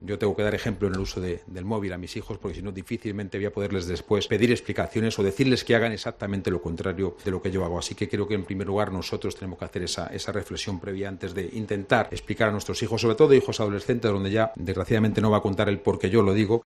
AUDIO: Ángel Garrido, presidente de la Comunidad de Madrid